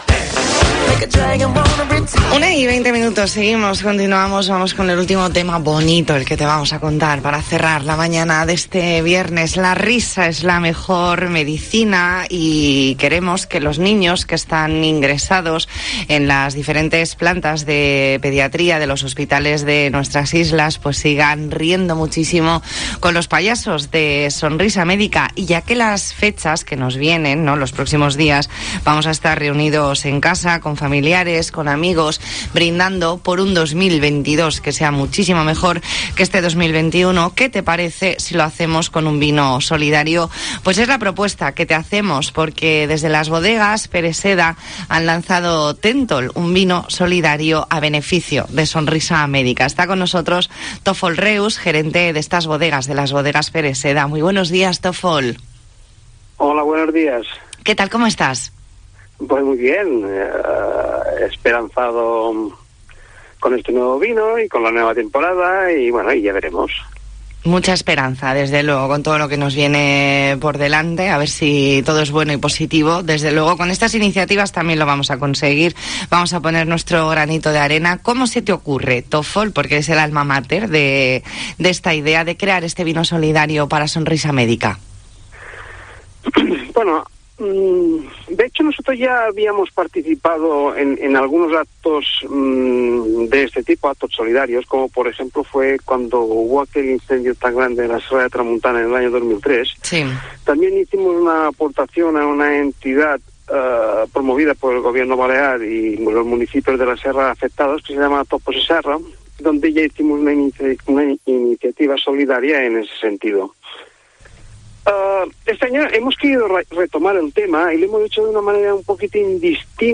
Entrevista en La Mañana en COPE Más Mallorca, viernes 17 de diciembre de 2021.